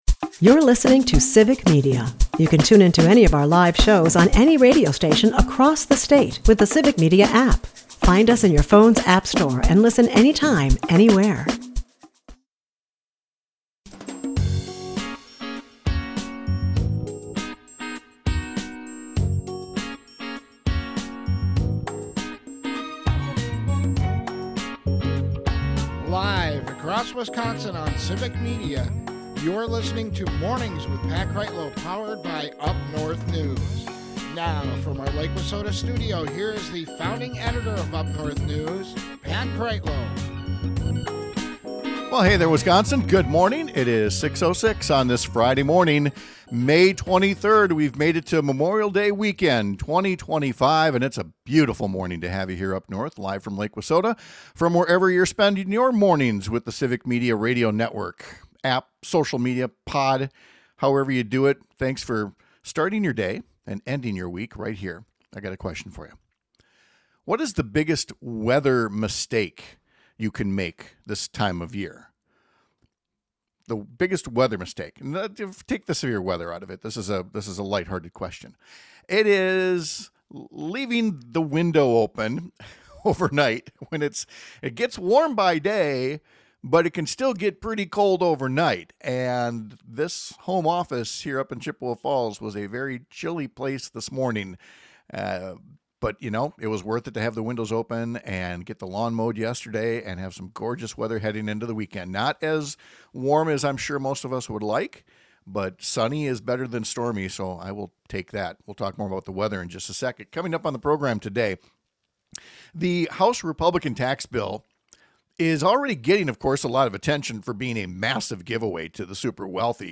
Mornings with Pat Kreitlow airs on several stations across the Civic Media radio network, Monday through Friday from 6-9 am.